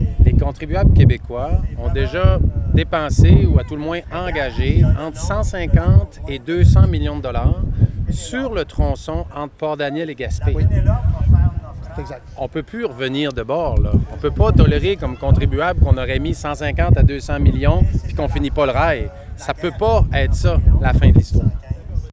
Pour sa part, Alexis Deschênes rappelle que les contribuables ont déjà investi trop d’argent dans le tronçon Port-Daniel-Gaspé pour permettre au gouvernement de reculer dans le dossier :